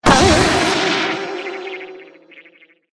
wood_joint_break_02.ogg